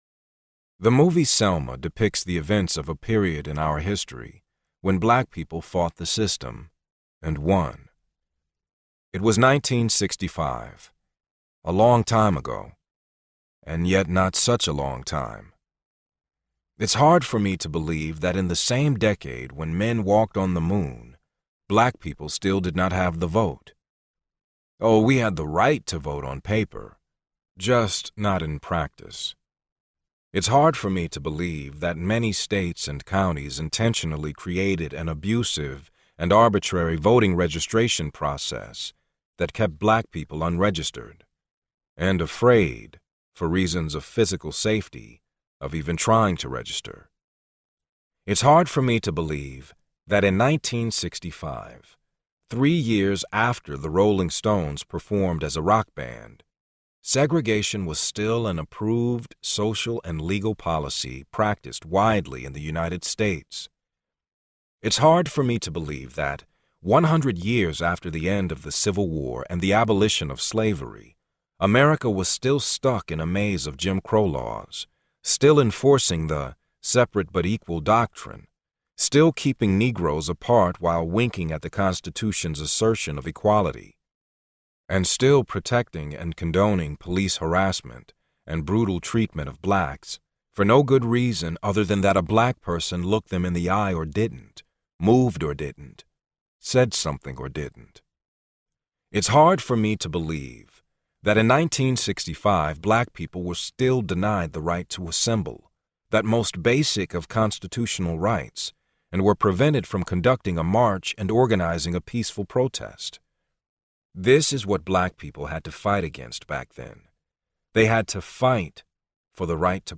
Under Our Skin Audiobook
Narrator
6.17 Hrs. – Unabridged